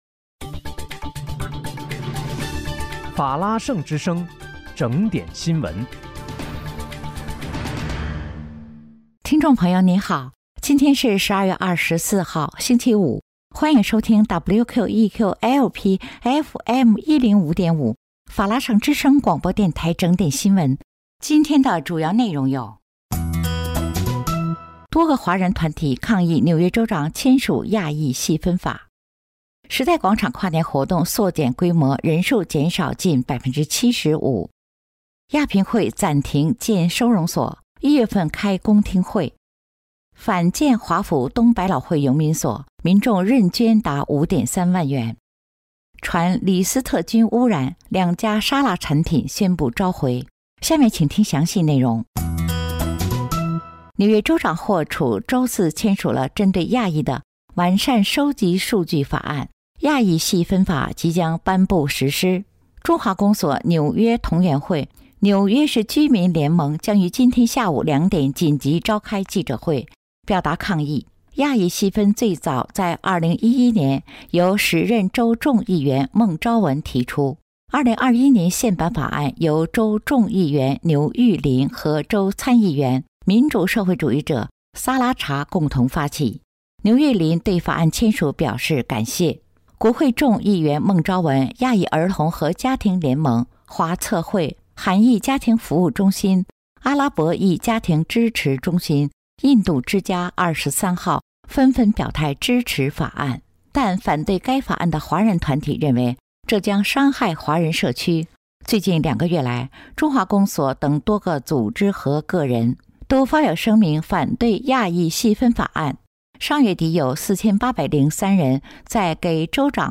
12月24日（星期五）纽约整点新闻